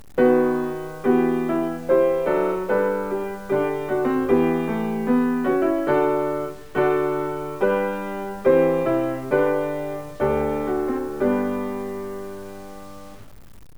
You will hear a recording of Bach chorale played on piano